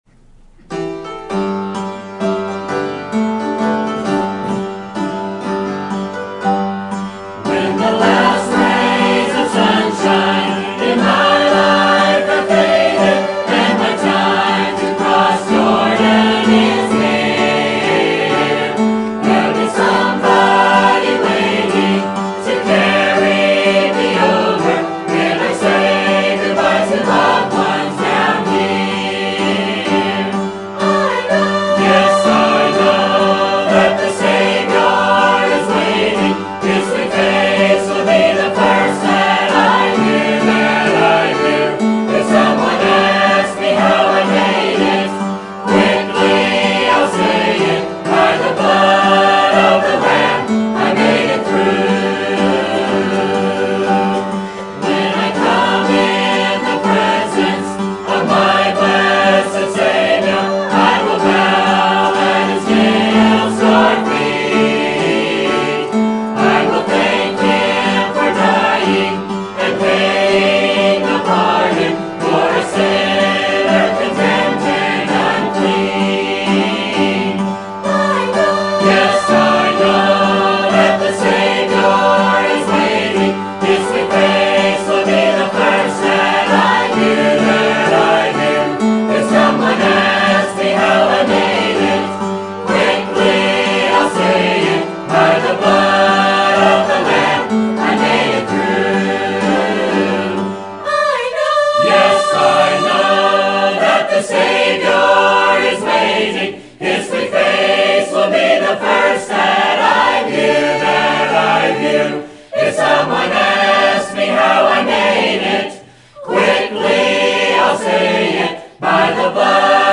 Sermon Topic: Spring Revival Sermon Type: Special Sermon Audio: Sermon download: Download (29.62 MB) Sermon Tags: Jeremiah Revival Message Veil